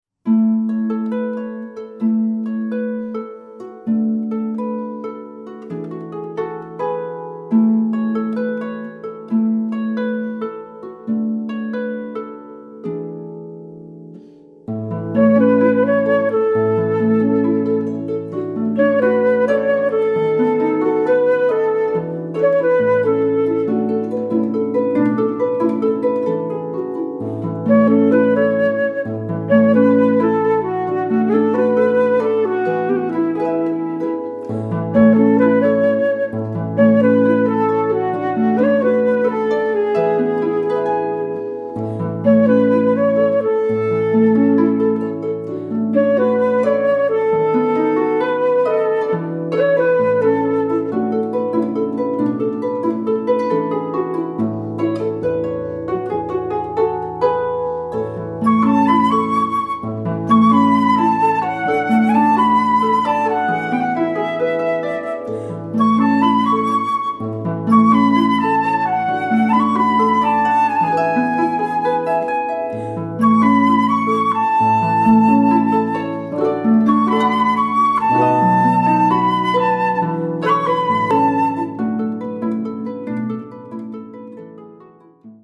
Jazz/Pop